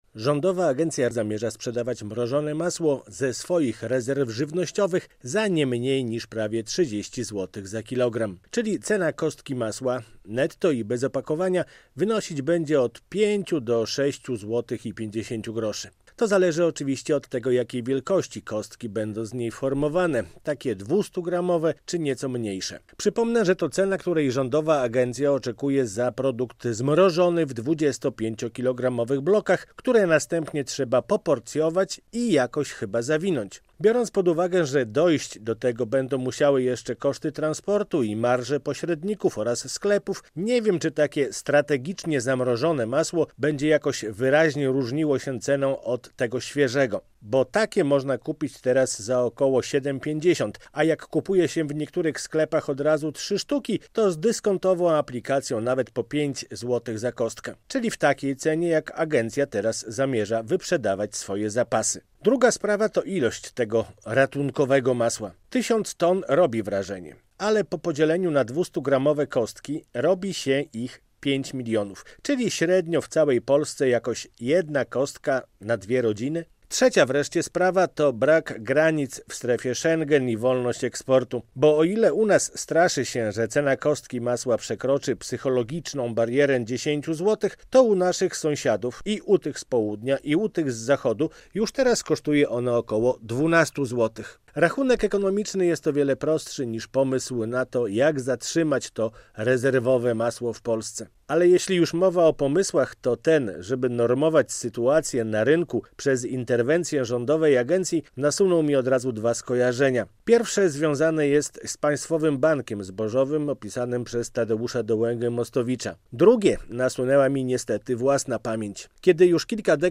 Masło z rezerw - felieton